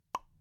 pop out, bubble, soft bursting
balloon bubble click game game-sound gentle menu pop sound effect free sound royalty free Sound Effects